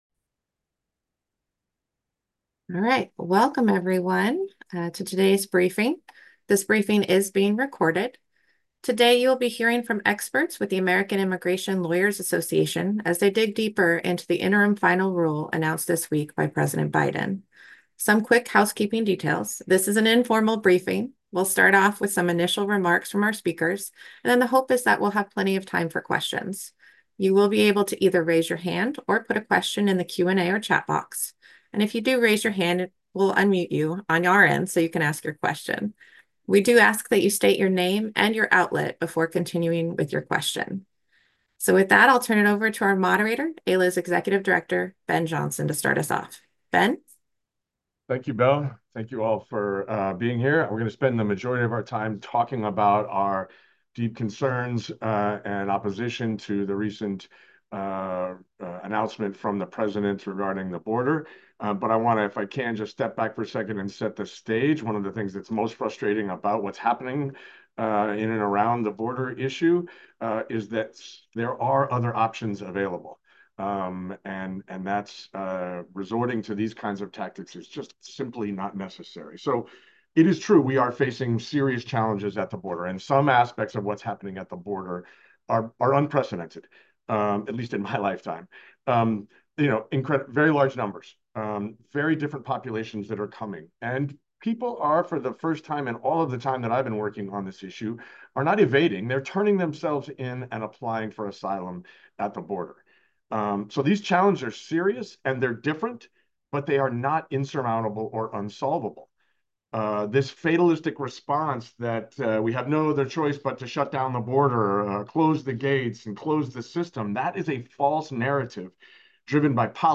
Audio Recording of Press Briefing Held June 6, 2024, Regarding Implementation of Border IFR
Unofficial transcription and audio recording of AILA's press briefing held on June 6, 2024, regarding the implementation of the recent border IFR.